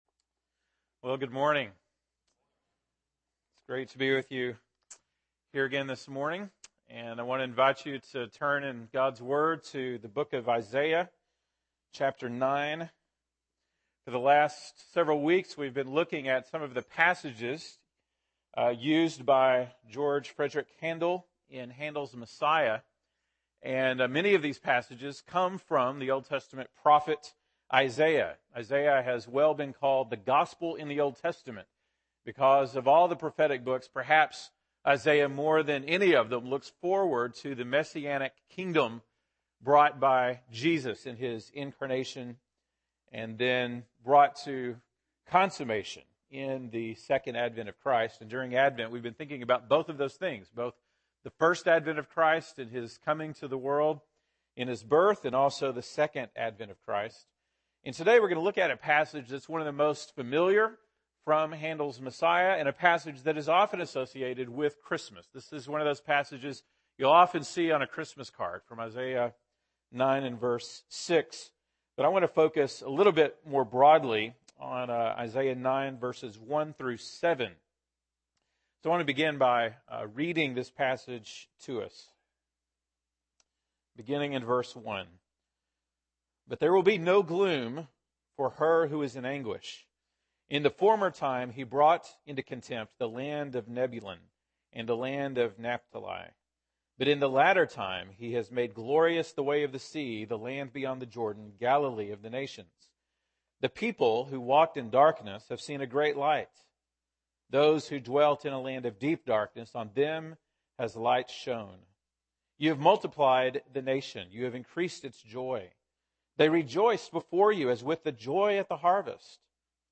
December 21, 2014 (Sunday Morning)